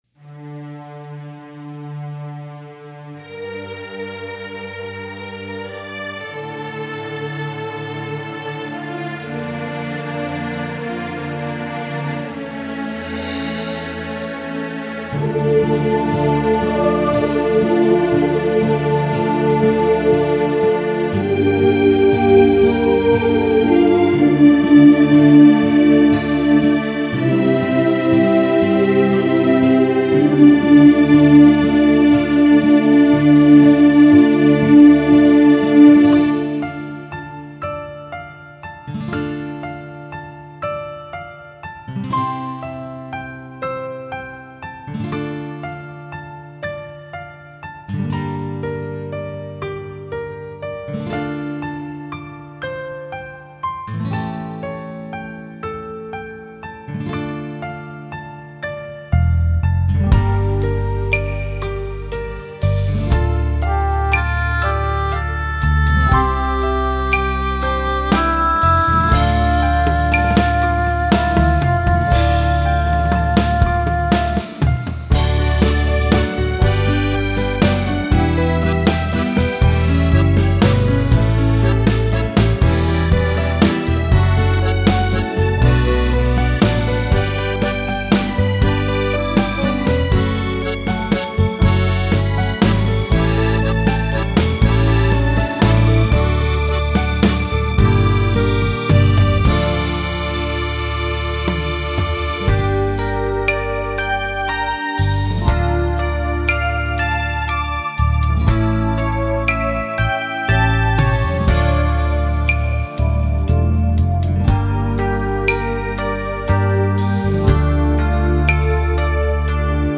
Nghe nhạc hòa tấu karaoke: